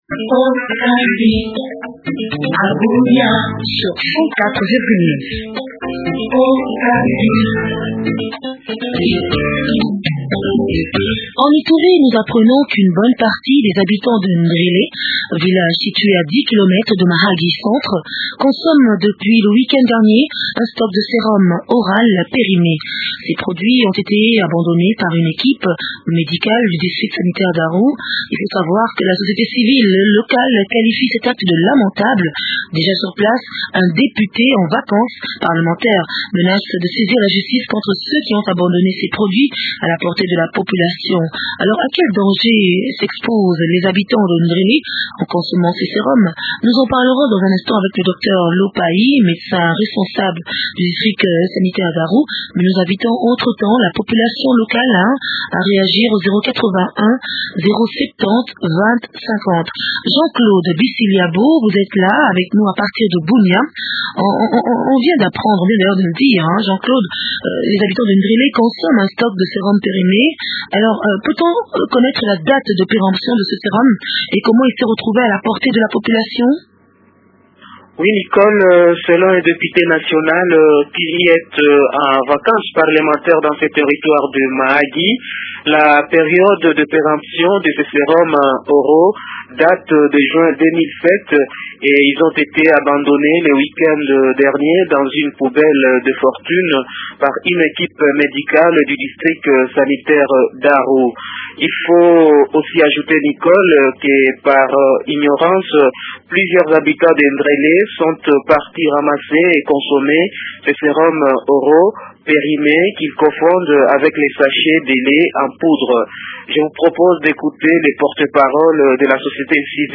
font le point avec l’administrateur de Mahagi